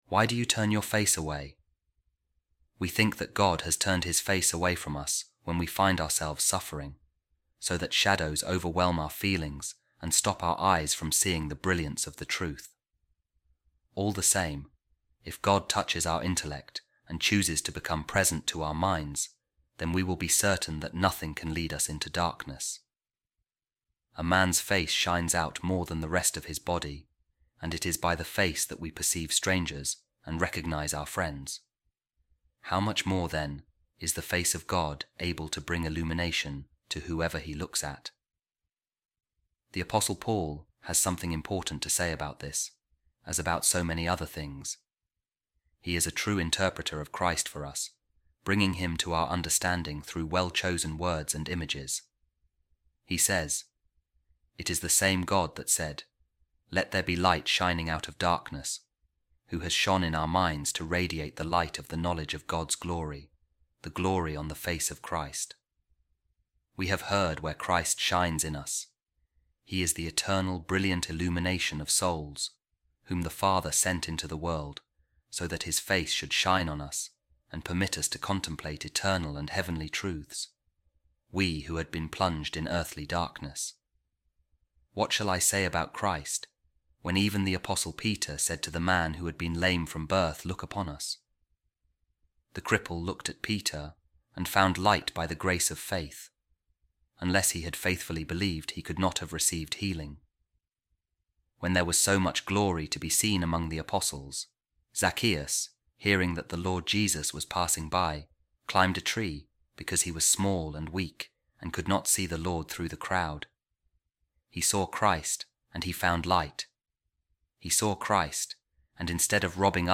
A Reading From The Discourses Of Saint Ambrose On The Psalms | Light Of God’s Countenance